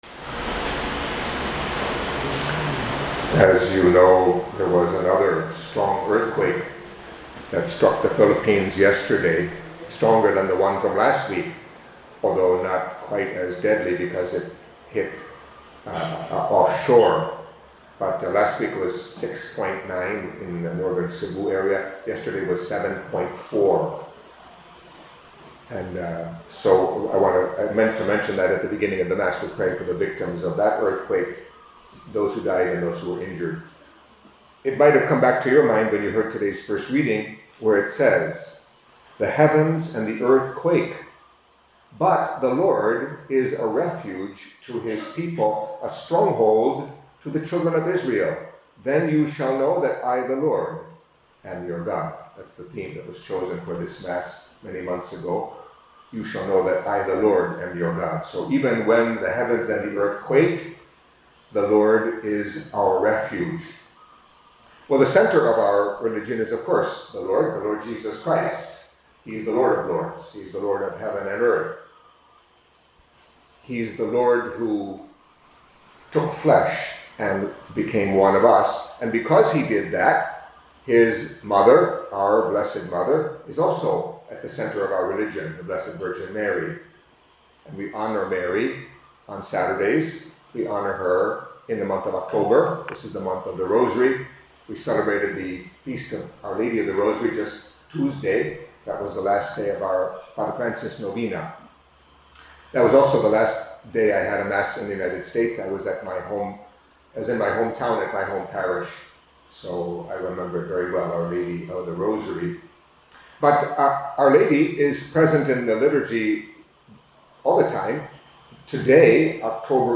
Catholic Mass homily for Saturday of the Twenty-Seventh Week in Ordinary Time